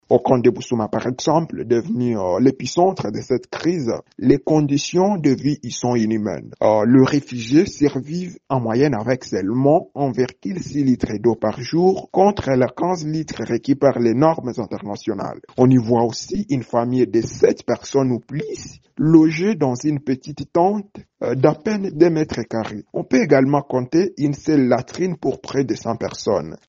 ELEMENT-BURUNDI-FR-.mp3